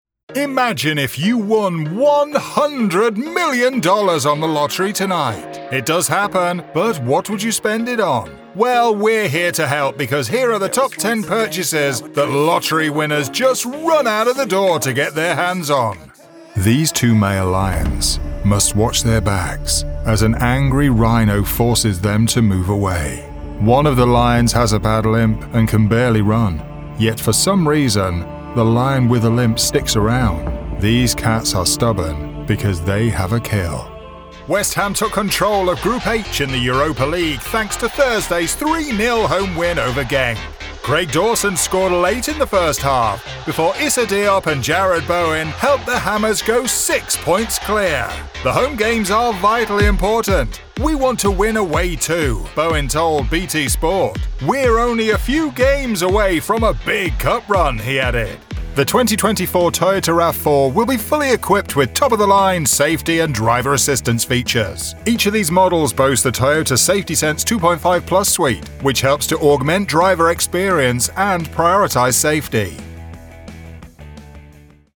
Announcer
Male , Mid-Range